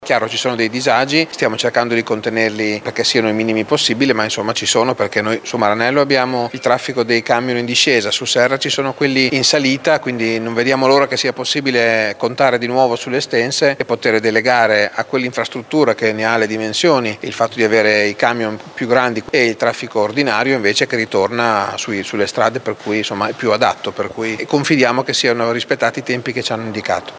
Nel frattempo la chiusura della nuova estense nel tratto tra Serramazzoni e Pavullo sposta il traffico pesante sulla via Giardini, notevoli i disagi come spiega il sindaco di Maranello Luigi Zironi: